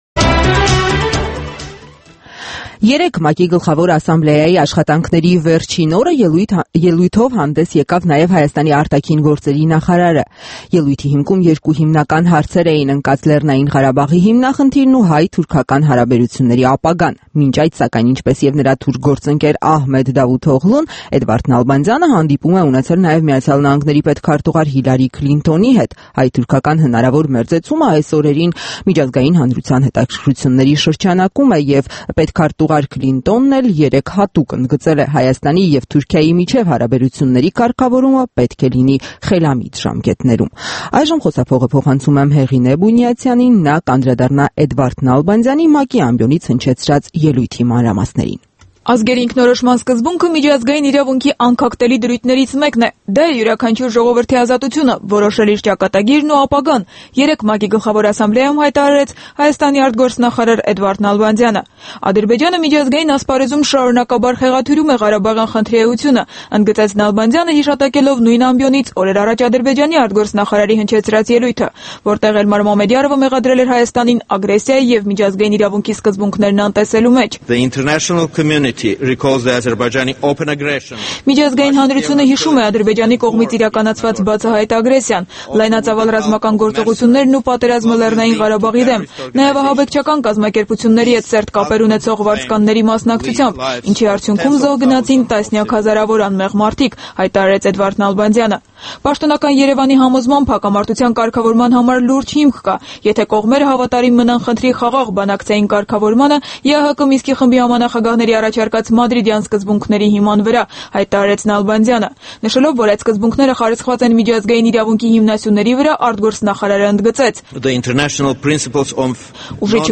Էդվարդ Նալբանդյանի ելույթը ՄԱԿ-ում